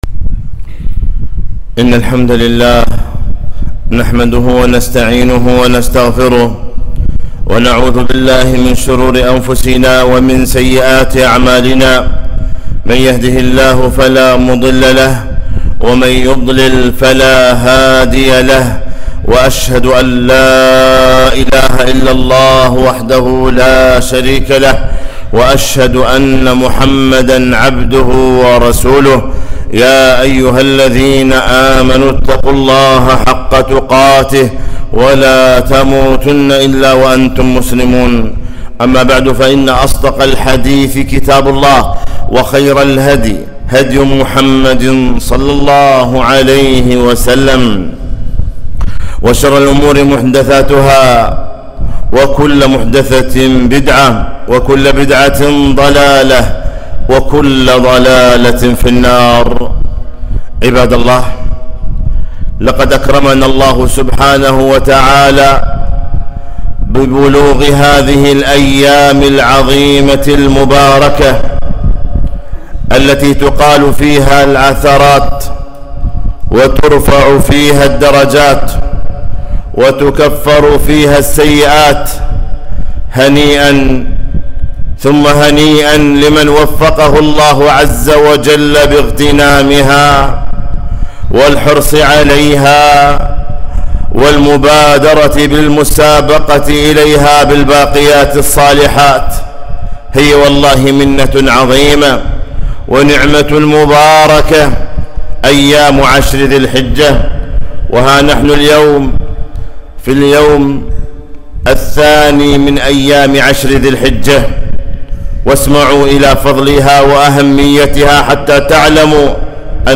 خطبة - أفضل أيام الدنيا فاغتنموها